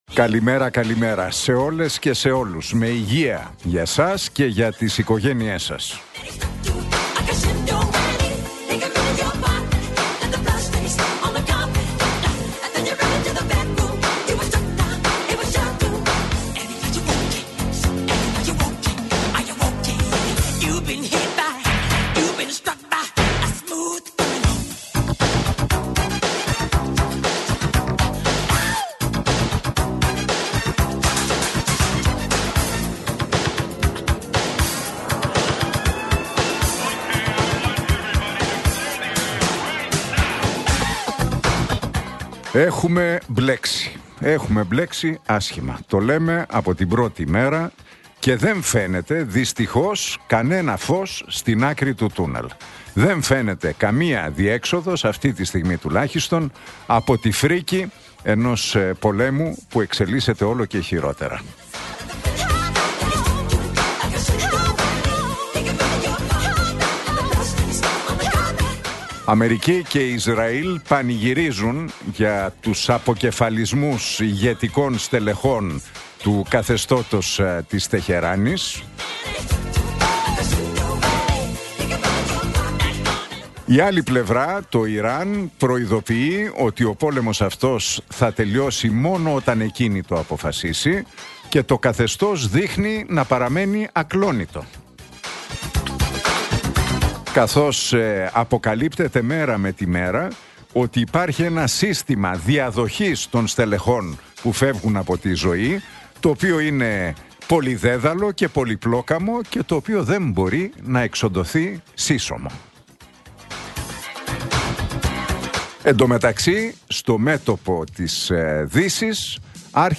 Ακούστε το σχόλιο του Νίκου Χατζηνικολάου στον ραδιοφωνικό σταθμό Realfm 97,8, την Τετάρτη 18 Μαρτίου 2026.